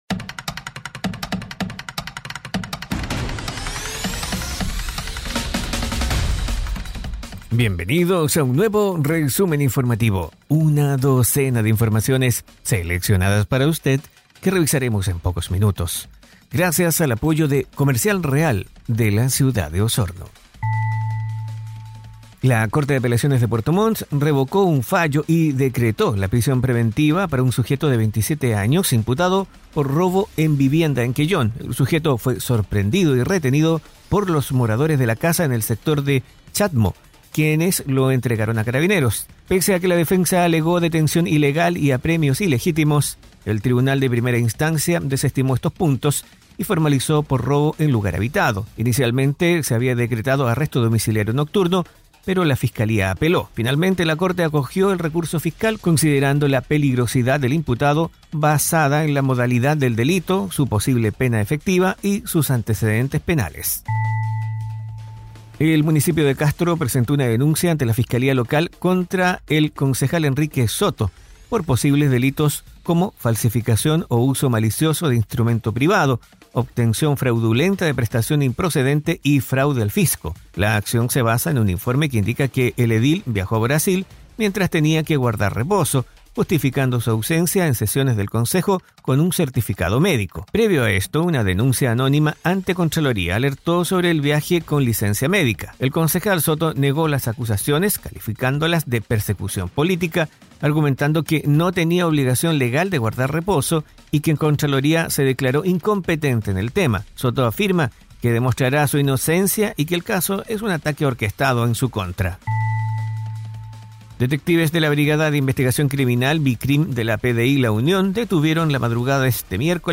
Resumen Informativo 🎙 Podcast 15 de octubre de 2025